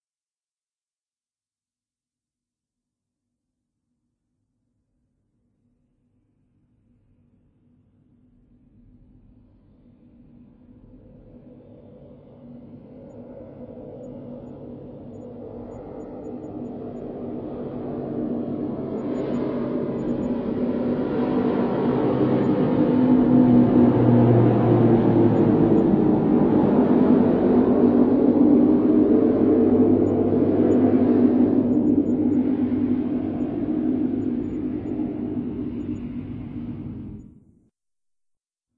直升机或飞机的螺旋桨
描述：通过旋转板制成的螺旋桨声。